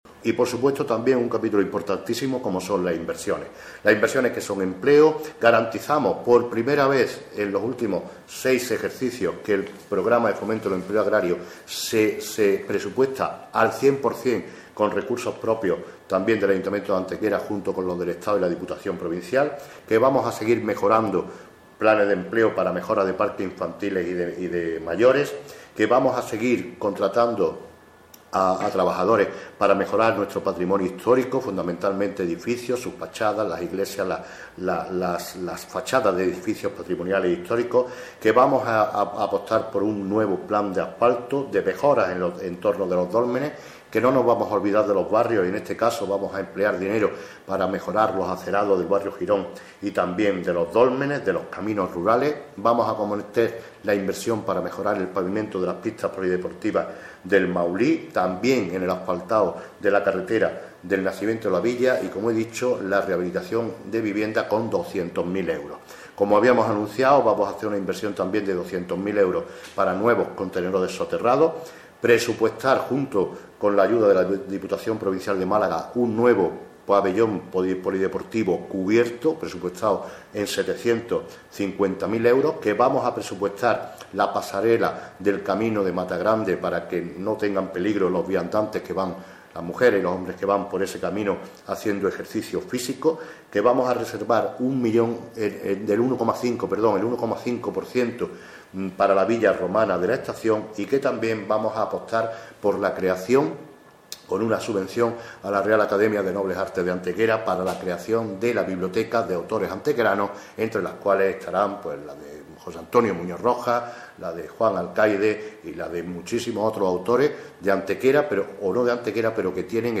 El alcalde de Antequera, Manolo Barón, ha presentado en la mañana de hoy los Presupuestos preliminares del Ayuntamiento para el año 2016, proyecto económico que será aprobado inicialmente en la sesión ordinaria del Pleno Municipal a celebrar este viernes 30 de octubre.